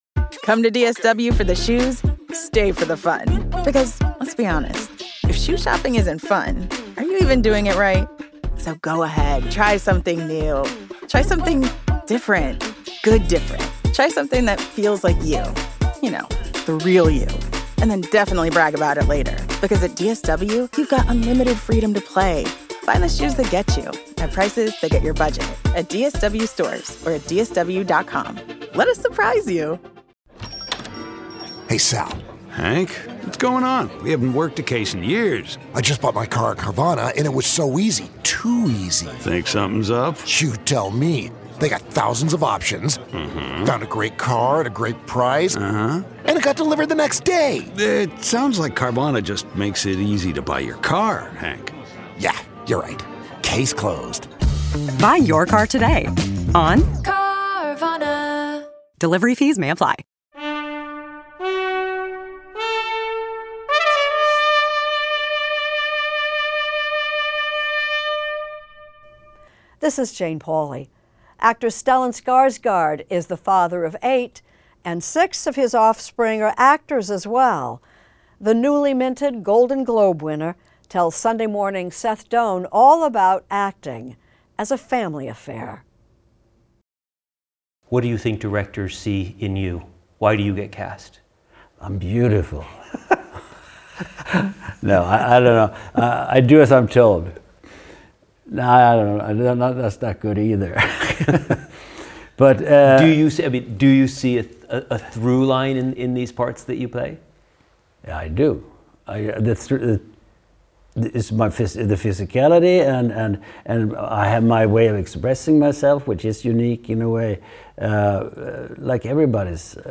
Extended Interview: Stellan Skarsgård